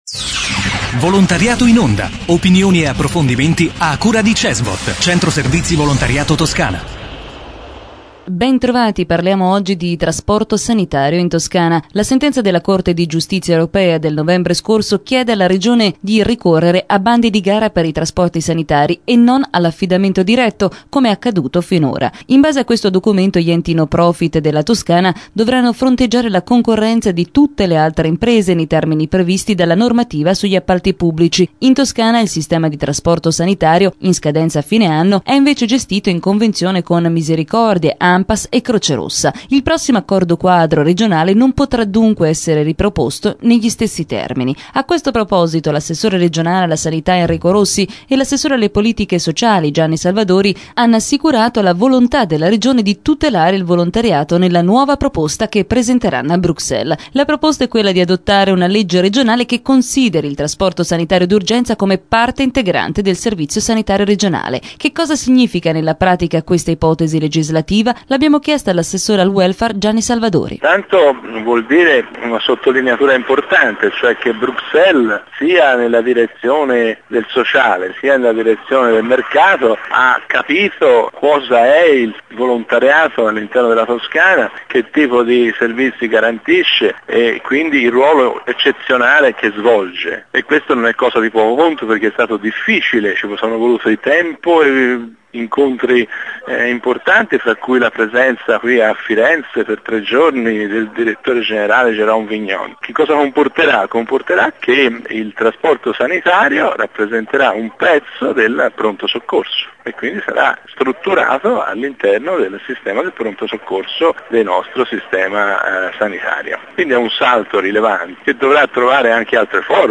Intervista a Gianni Salvadori, assessore alle politiche sociali della Regione Toscana